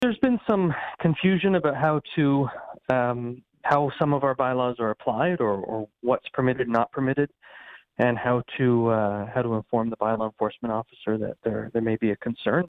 Mayor of Brighton Brian Ostrander.